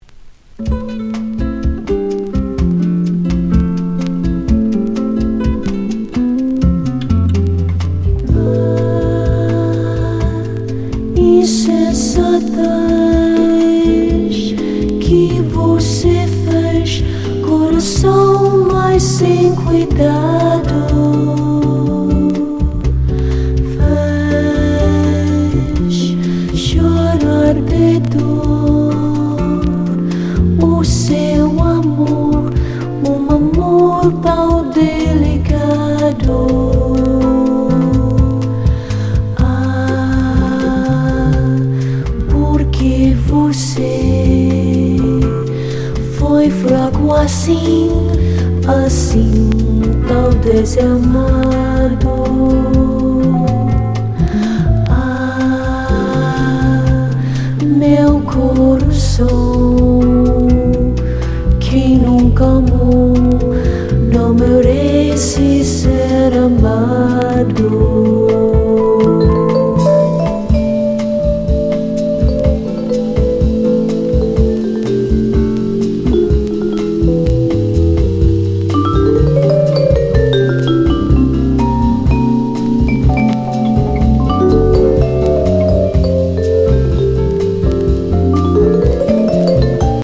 ジャジー＆ラテン・ソフトロック好盤！